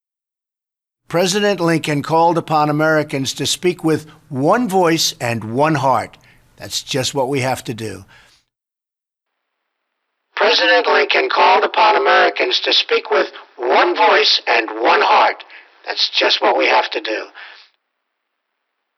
How to simulate single sideband modulation effect